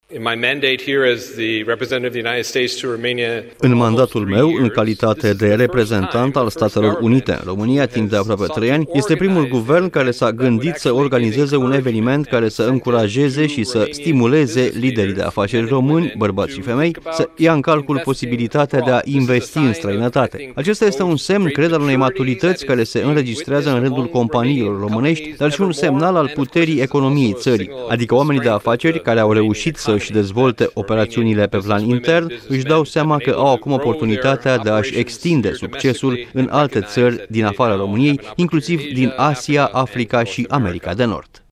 Ambasadorul american la Bucureşti, Hans Klemm, i-a invitat pe oamenii de afaceri români să investească în Statele Unite. Prezent, la un forum pe teme economice organizat la Bucureşti, Hans Klemm a remarcat faptul că, în premieră, în mandatul său, un guvern român organizează un asemenea eveniment. El consideră că este o ocazie foarte bună pentru investitorii români să-şi extindă afacerile în străinătate şi un semnal că economia se dezvoltă: